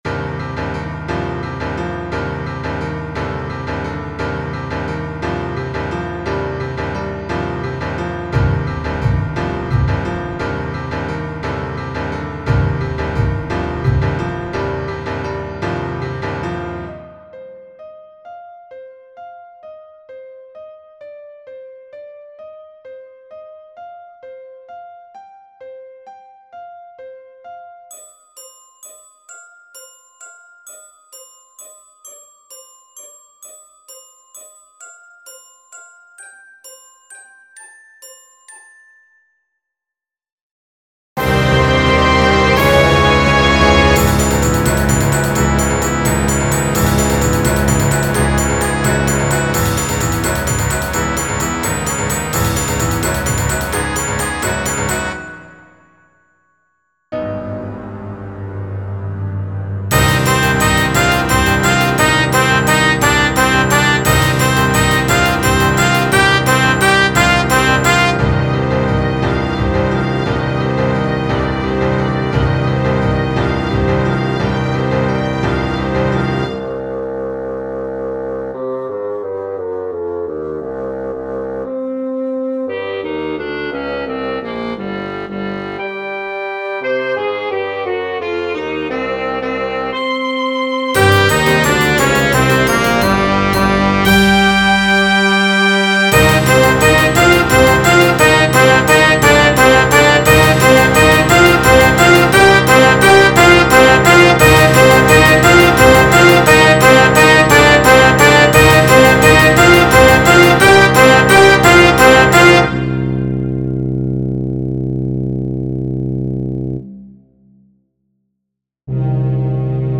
der sich durch einen lauten und machtvollen Klang auszeichnet
der sich durch einen ruhigen Klang auszeichnet
der sich durch einen rhythmisch komplizierten Klang auszeichnet
Es wird von einem großen Orchester aufgeführt, das Streicher, Bläser, Percussion-Instrumente und Klavier umfasst